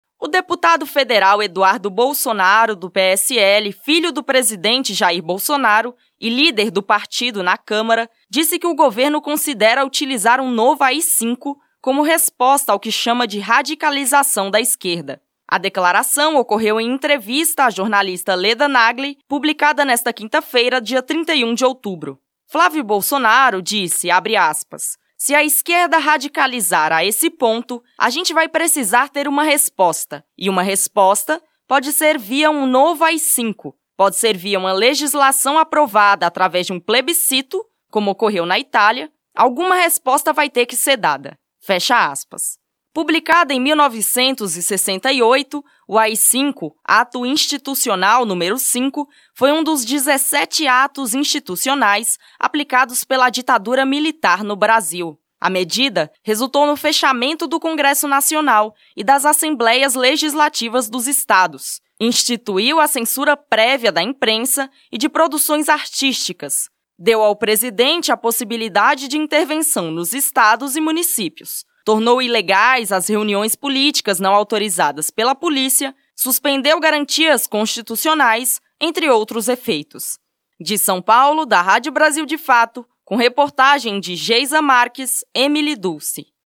O deputado federal Eduardo Bolsonaro (PSL-RJ), filho do presidente Jair Bolsonaro (PSL), e líder do partido na Câmara, disse em entrevista à jornalista Leda Nagle publicada nesta quinta-feira (31) que o governo considera utilizar um "novo AI-5", como resposta ao que chama de radicalização da esquerda.